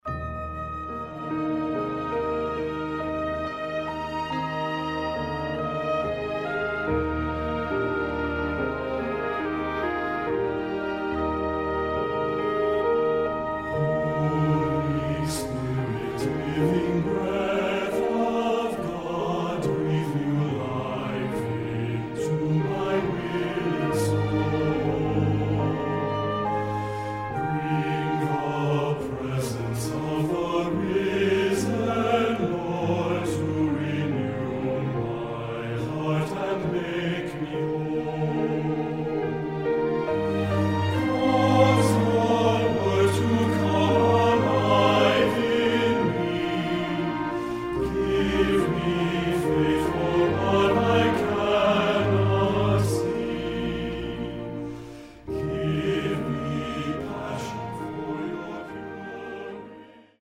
Voicing: Full Orch,